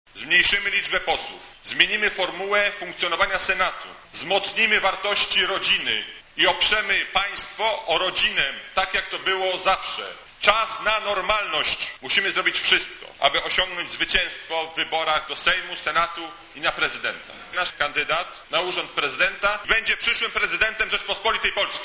Rozpoczynamy marsz do IV Rzeczypospolitej - oświadczył lider LPR Roman Giertych, podczas sobotniego Kongresu partii.